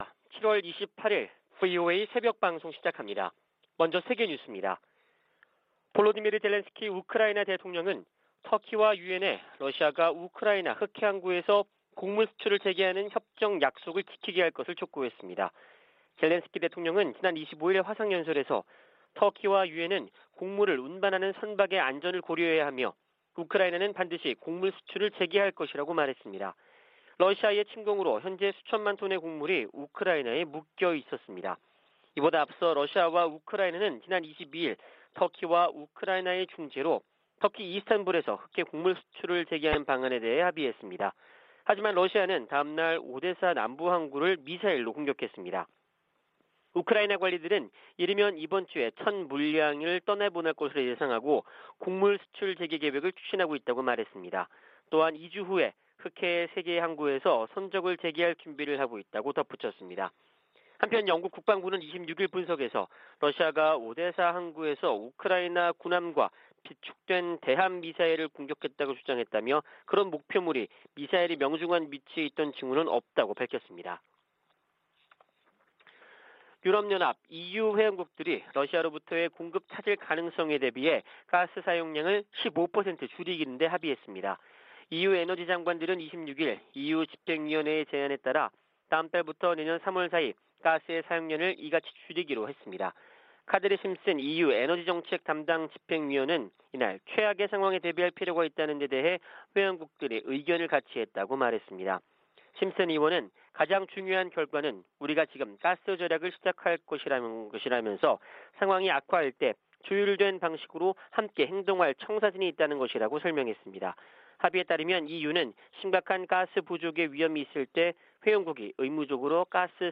VOA 한국어 '출발 뉴스 쇼', 2022년 7월 28일 방송입니다. 미국 정부가 북한의 추가 핵실험이 한반도의 불안정성을 가중시킬 것이라며 동맹과 적절히 대응할 것이라고 밝혔습니다. 박진 한국 외교부 장관이 북한이 7차 핵실험을 감행하면 더 강력한 국제사회 제재에 직면할 것이라고 경고했습니다. 미국의 비확산 담당 고위관리가 핵확산금지조약 평가회의를 계기로 국제사회가 북한의 추가 핵실험과 미사일 시험을 규탄하기 기대한다고 밝혔습니다.